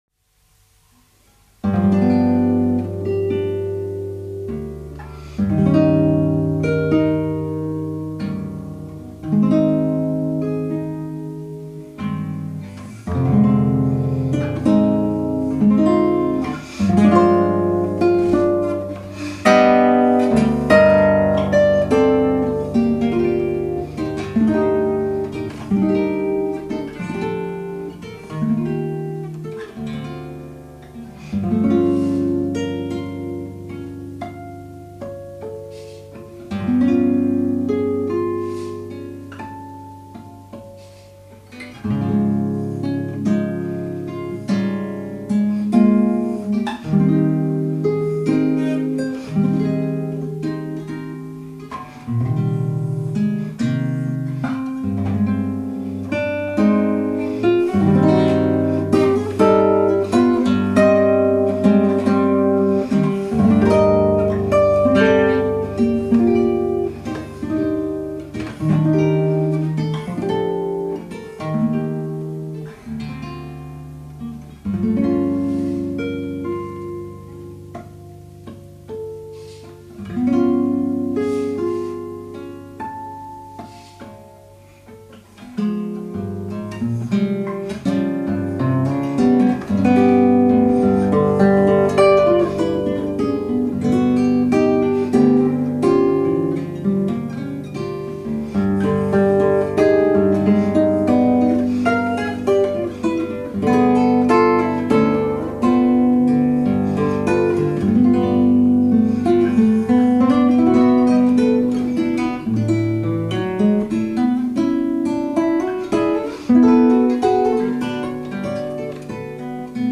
contemporary romantic composition
solo Seven String Russian Guitar